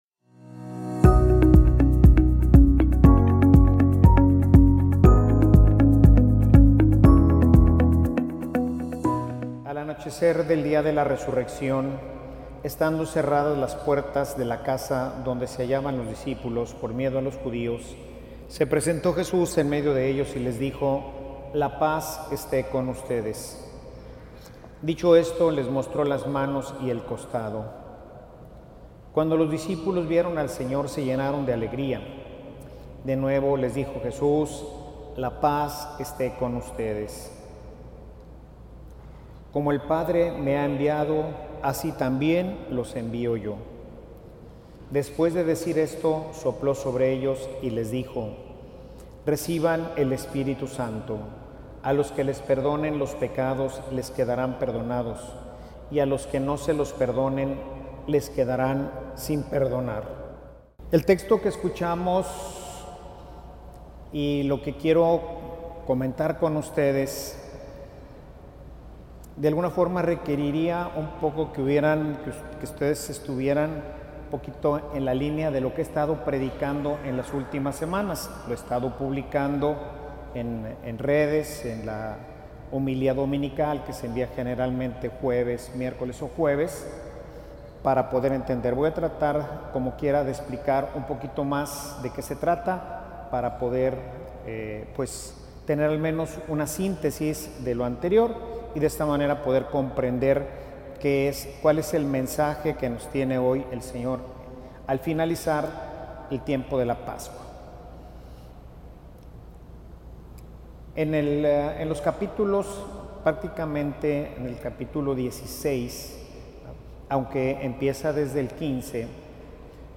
Homilia_Compartimos_el_mismo_Espiritu.mp3